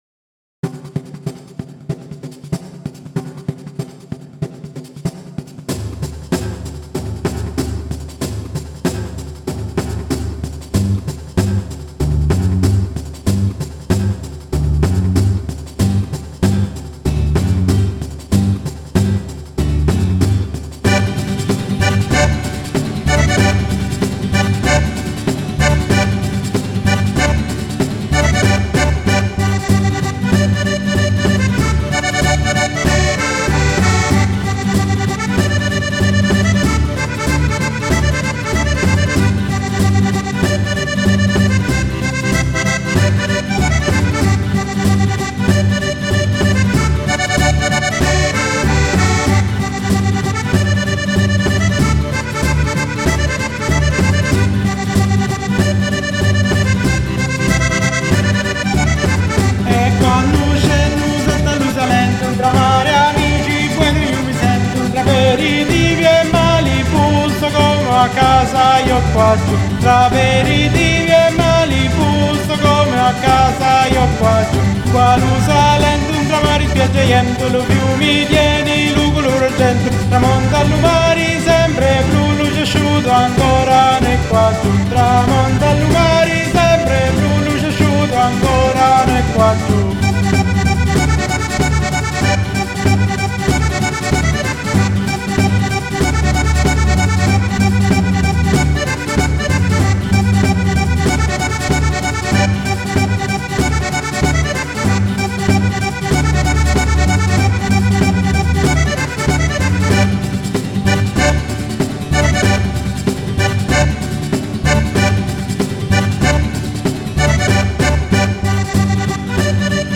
canzone Pizzica salentina